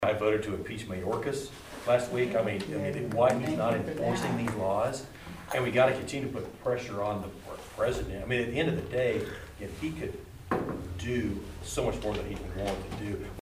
United States Congressman Tracey Mann visited Riley County on Thursday for a Town Hall meeting at the Leonardville Community Center.